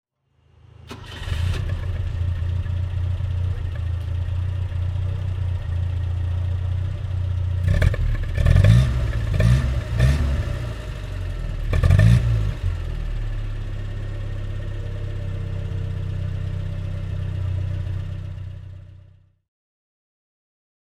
Peugeot 204 Coupé (1968) - Starten und Leerlauf